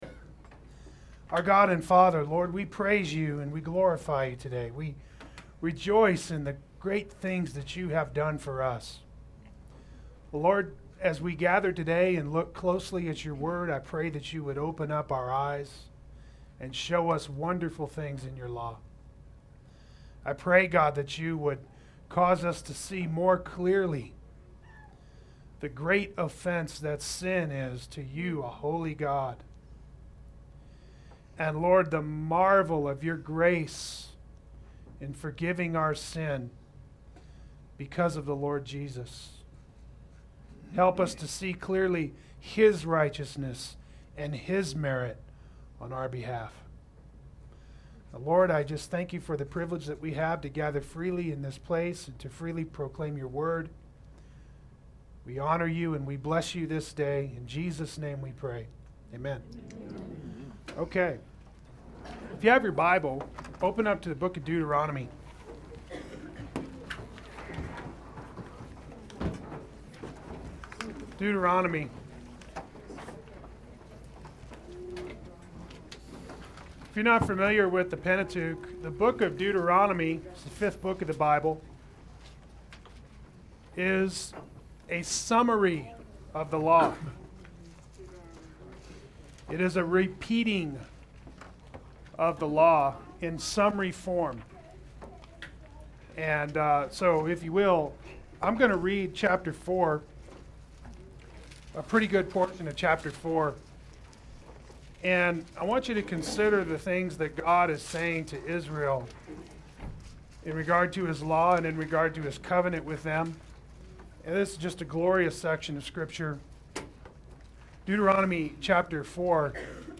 The Mosaic Law and the Nature of God Adult Sunday School